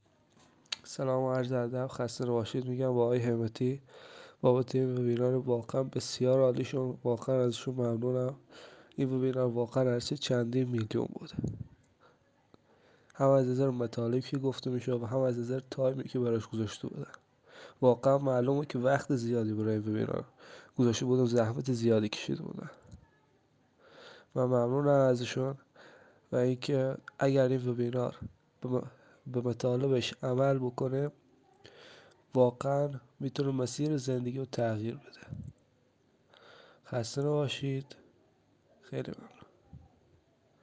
نظرات صوتی شرکت کننده های وبینار درآمد دلاری